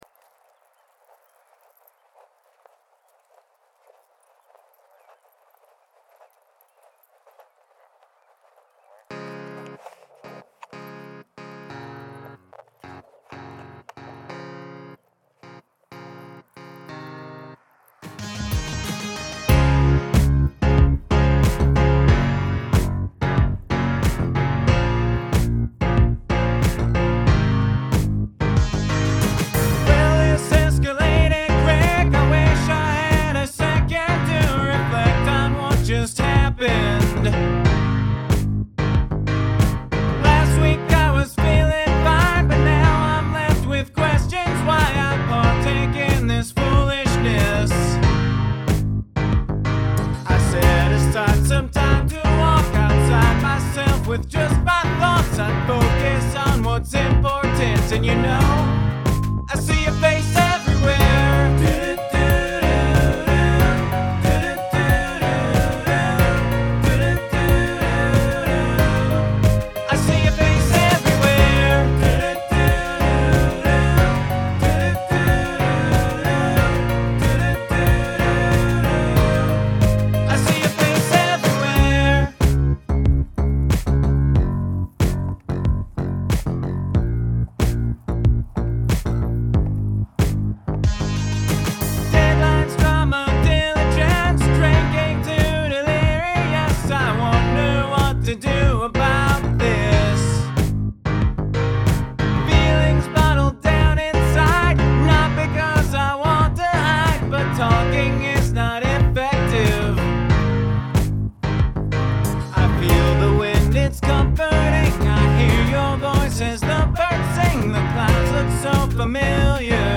Use of field recording
Love the do dos. Simple but cool bass line.